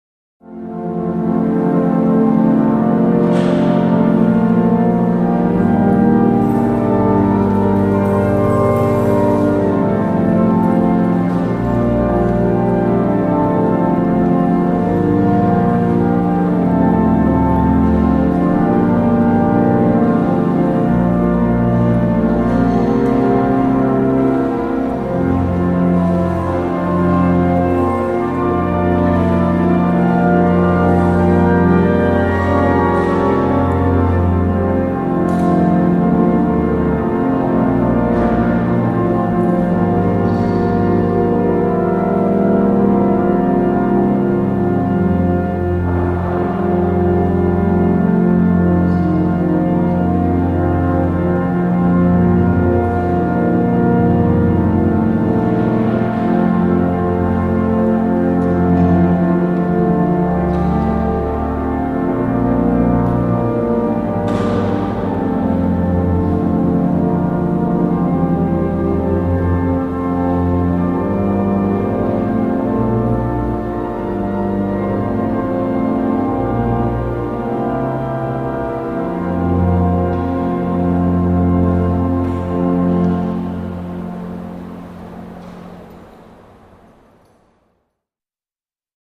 Cathedral Organ Plays During The Taking Of Communion; Light Movement Of Worshippers Etc. - Priests Etc. Bustle At End